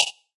重弦
描述：很多深沉的弦乐样本，并不是所有的样本都在预览文件中出现。
标签： music alsa_modular_synth ams chorus2 dark environment g2reverb lfo lin_vca mcv midi movie music_for_film mvclpf noise orchestral patch seq24 sinister suspenseful vco violin
声道立体声